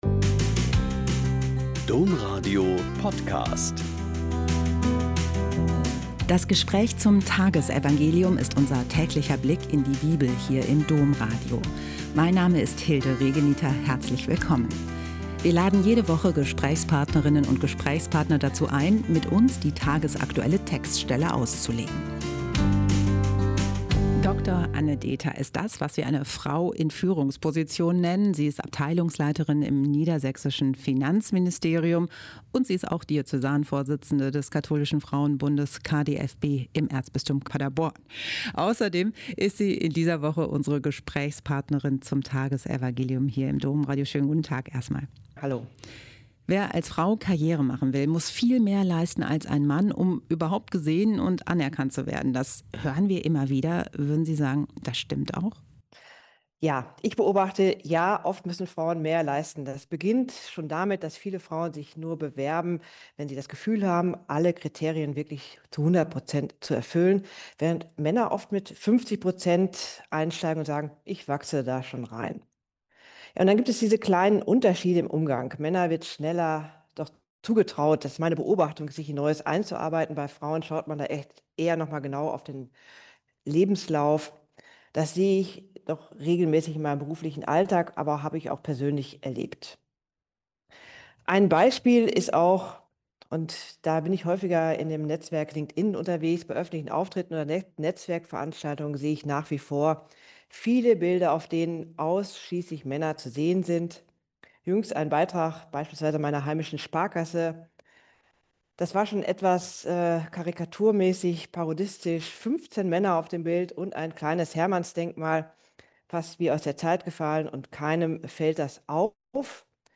Mt 11,25-27 - Gespräch